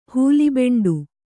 ♪ huli beṇḍu